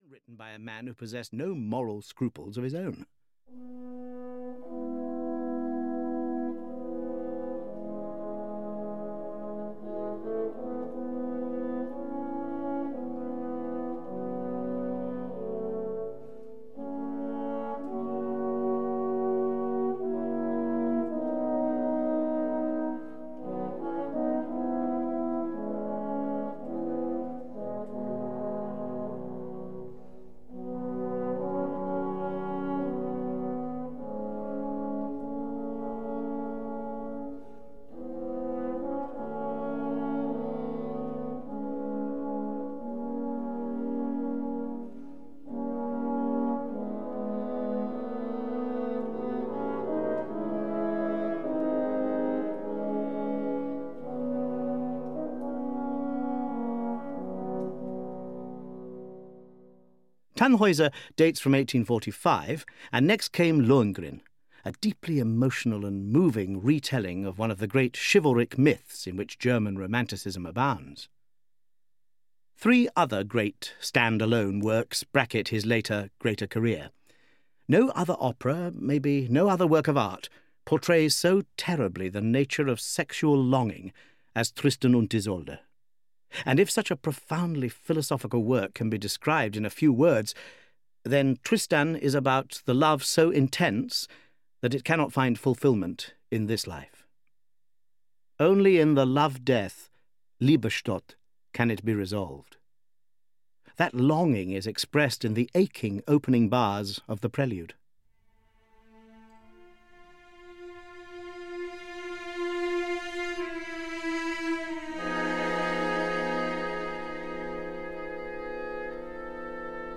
Audio knihaOpera Explained – The Flying Dutchman (EN)
Ukázka z knihy